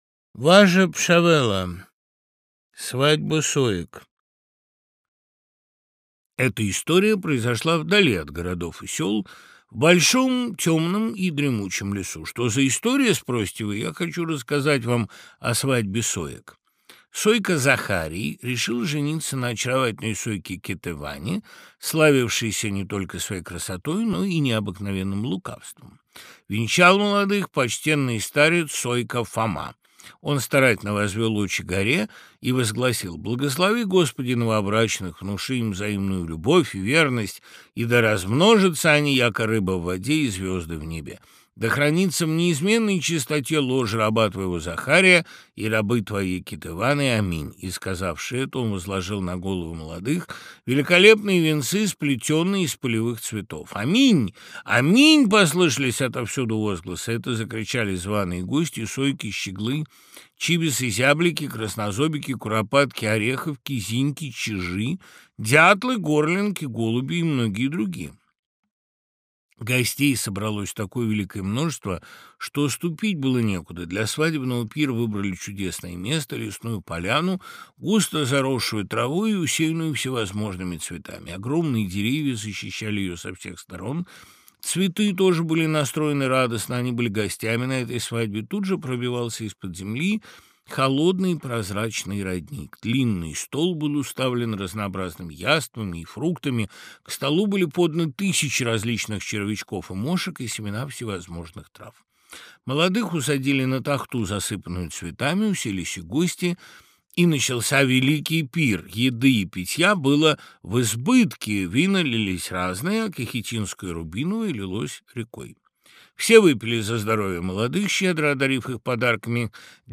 Аудиокнига Свадьба соек в исполнении Дмитрия Быкова + Лекция Быкова Д. | Библиотека аудиокниг
Aудиокнига Свадьба соек в исполнении Дмитрия Быкова + Лекция Быкова Д. Автор Дмитрий Быков Читает аудиокнигу Дмитрий Быков.